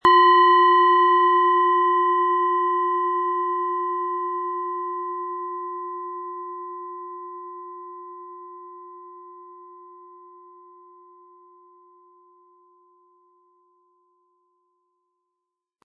Planetenschale® Erkenntnisse haben & Energetisch sein mit Wasser-Ton, Ø 12 cm inkl. Klöppel
Nach uralter Tradition von Hand getriebene Planetenklangschale Wasser.
Durch die überlieferte Herstellung hat diese Schale vielmehr diesen besonderen Ton und die innere Berührung der liebevollen Handfertigung.
MaterialBronze